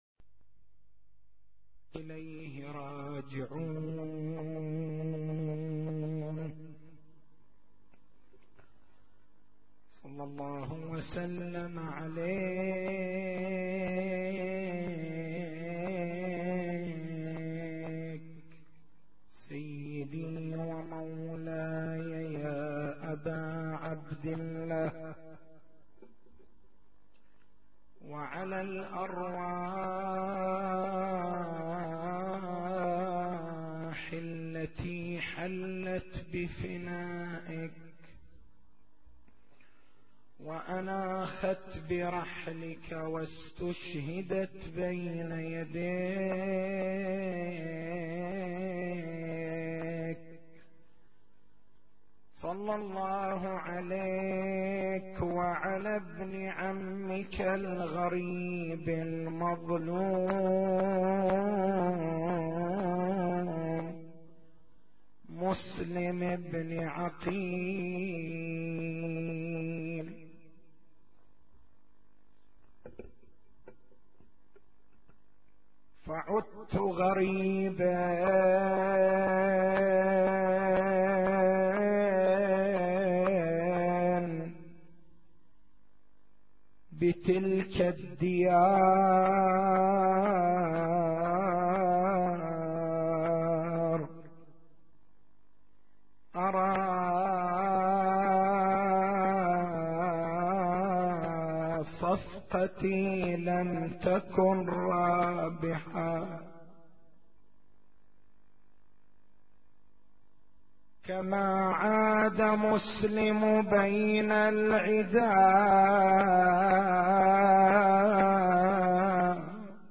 تاريخ المحاضرة: 04/01/1433 نقاط البحث: علاقة عملية التمحيص بغيبة الإمام المهدي (ع) الفرق بين العلّة والحكمة هل التمحيص علّة الغيبة، أم هو مجرّد هدف من أهدافها وحكمة من حِكَمها؟